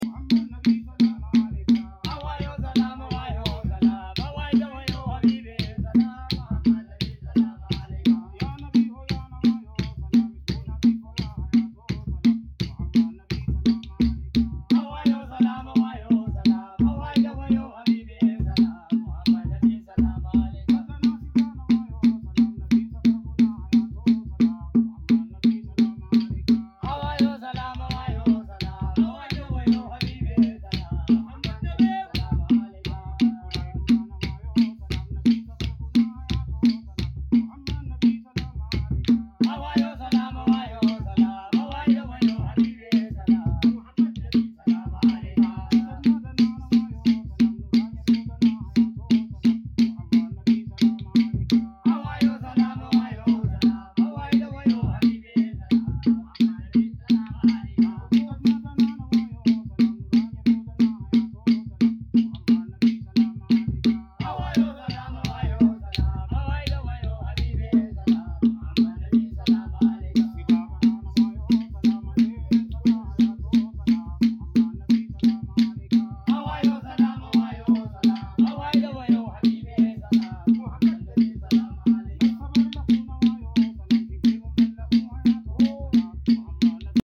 Sacred Harari music
Nasheed
SufiMusic
Chanting